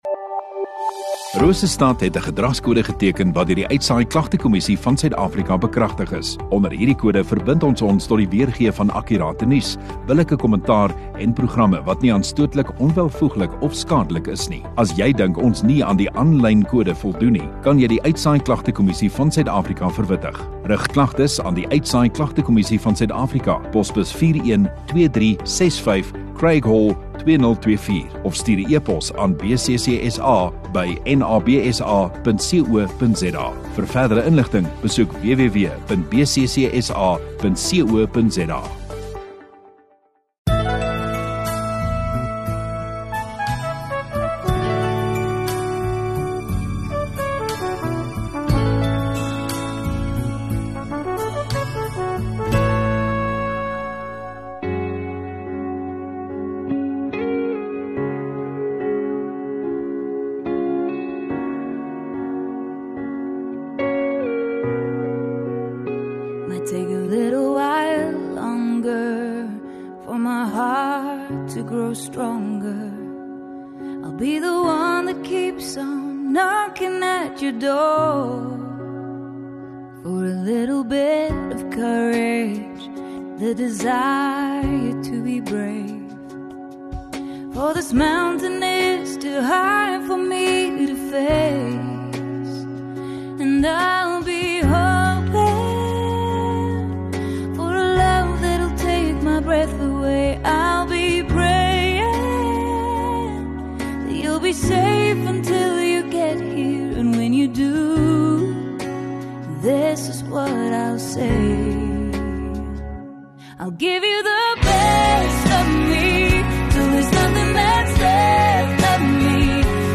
12 Jan Sondagaand Erediens